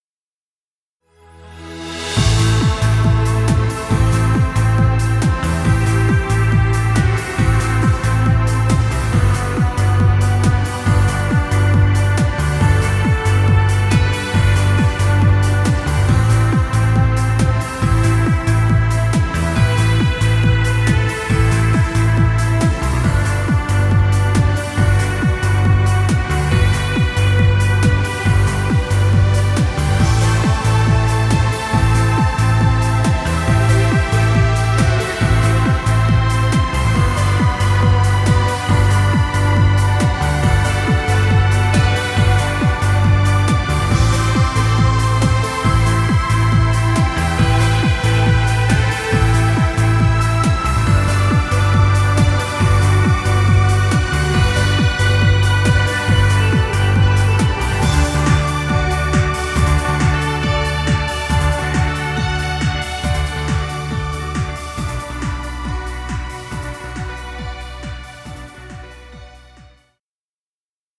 Dance music.